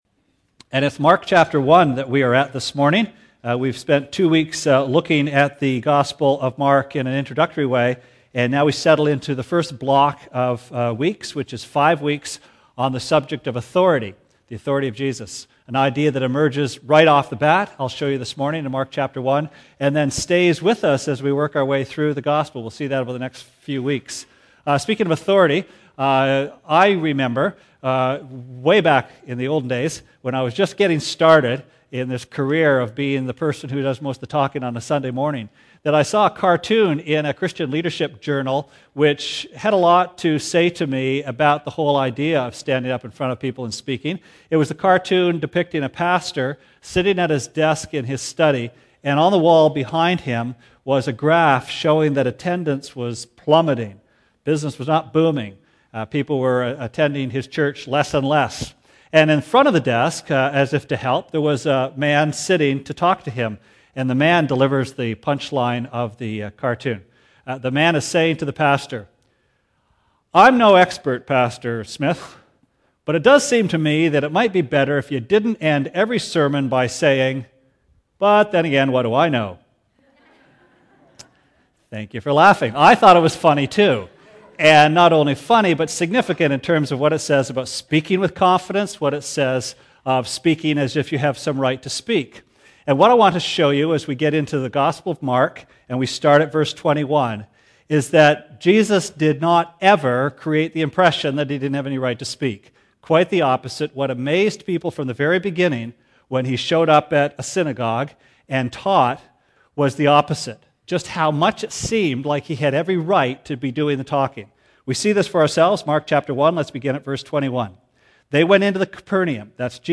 Sermon Archives January 18, 2009: Master and Commander This morning we begin the first of five sermons from the Gospel of Mark on the authority of Jesus.